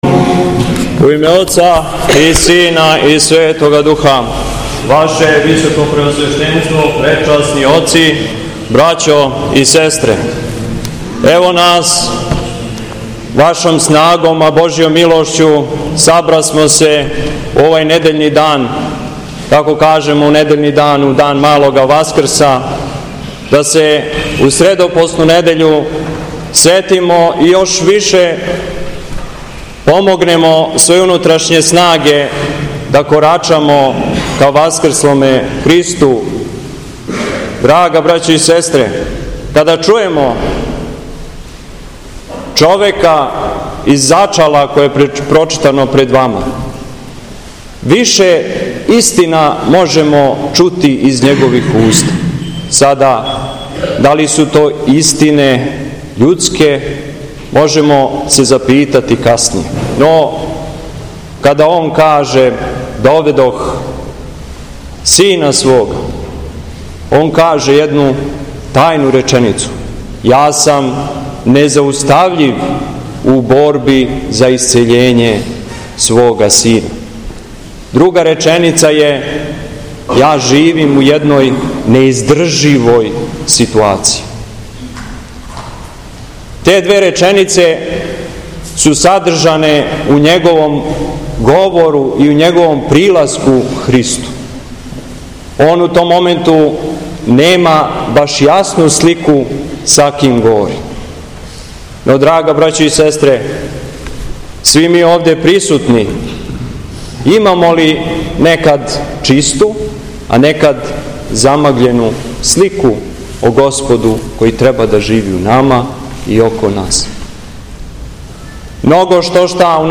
СВЕТА АРХИЈЕРЕЈСКА ЛИТУРГИЈА У ХРАМУ СВЕТОГА САВЕ У КРАГУЈЕВАЧКОМ НАСЕЉУ АЕРОДРОМ - Епархија Шумадијска
Беседа Његовог Високопреосвештенства Митрополита шумадијског г. Јована